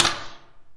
q3rally/baseq3r/sound/weapons/grenade/hgrenb1a.ogg at e6adaa03531c4da5b76f9a81f11b7447d8b30117